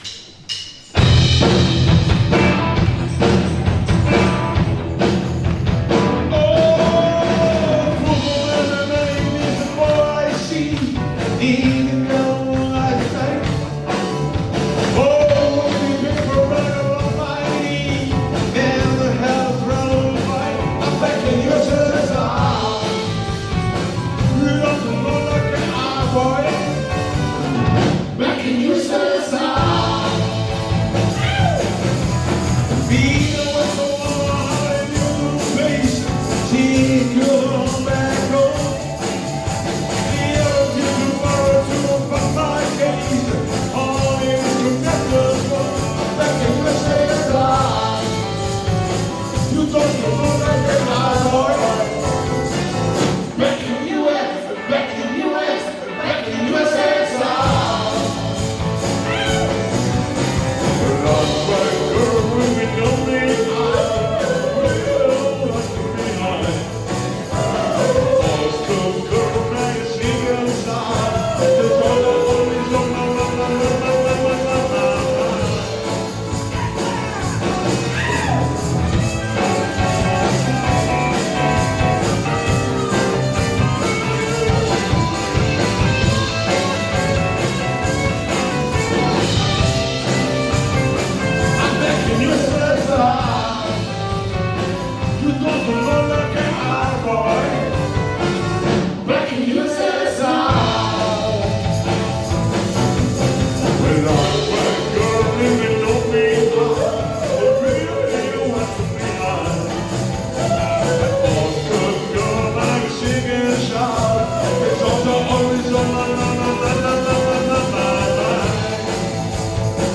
Concerto 26 novembre 2024
Corcagnano (Parma) – teatro Magliani strada Cavi di Vigatto 61